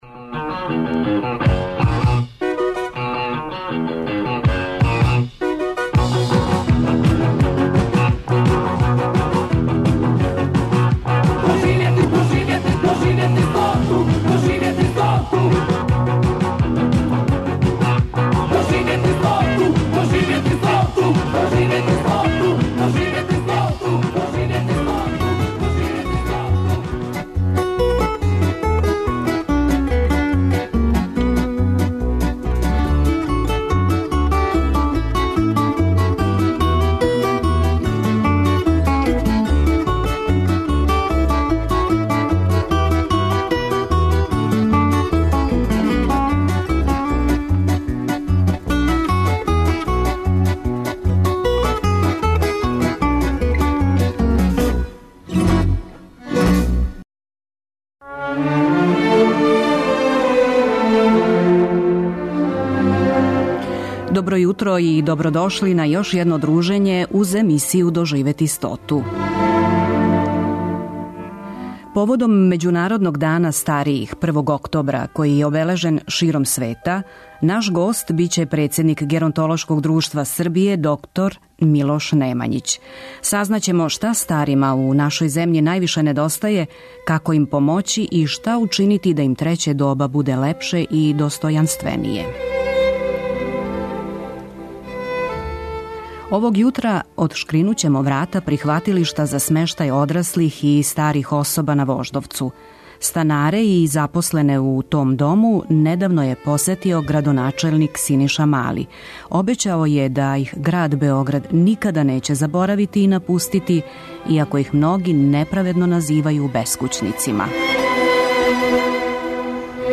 Емисија "Доживети стоту" Првог програма Радио Београда доноси интервјуе и репортаже посвећене старијој популацији.